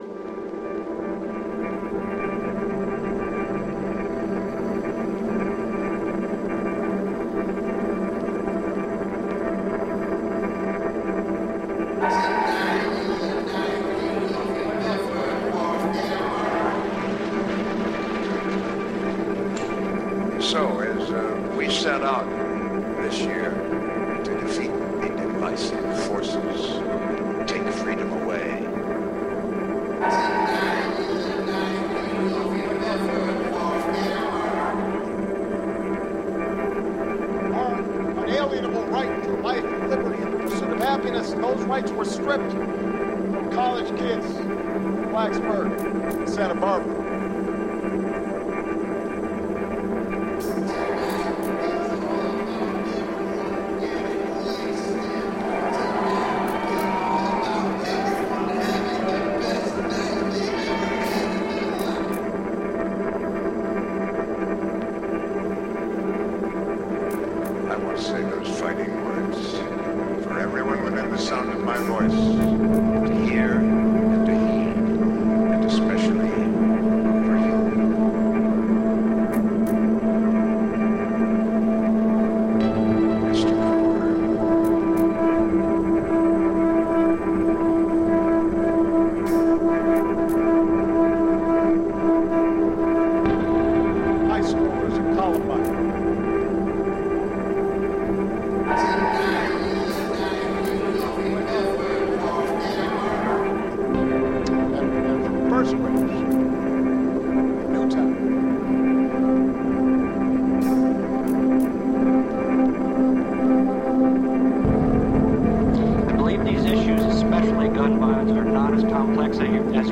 Andrew WK's show intro becomes a platform for the debate about gun control in the USA, over a bed of drones and sirens.